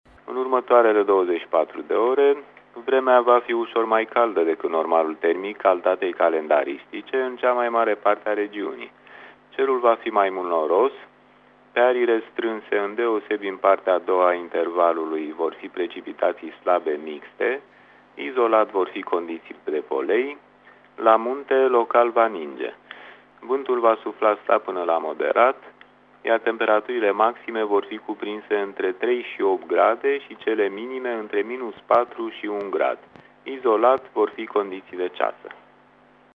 Prognoza meteo 30 decembrie (audio)